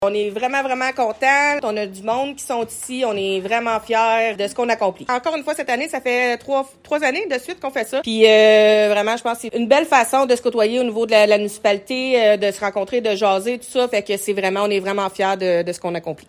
La mairesse de Montcerf-Lytton, Véronique Danis, se réjouit du succès de l’événement :